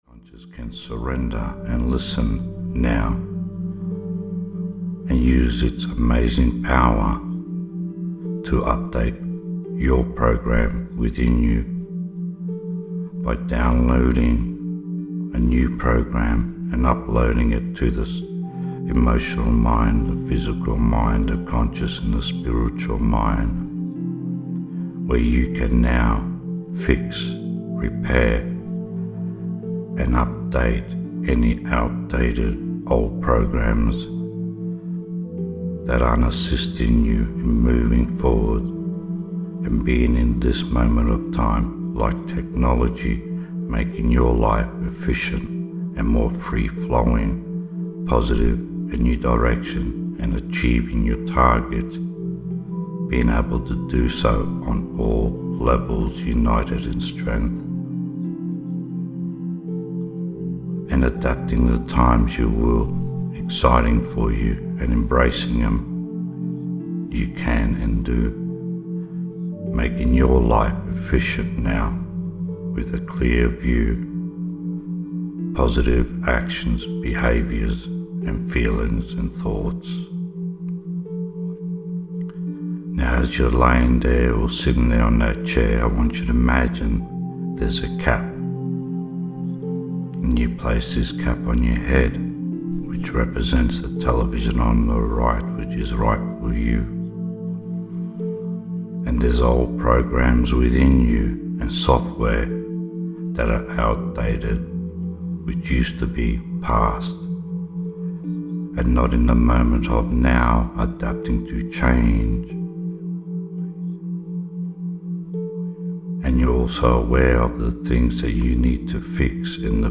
Experience deep restoration and holistic healing with this powerful guided hypnosis session. Designed to realign the mind, body, and soul, this audio promotes inner peace, energy balance, and emotional harmony.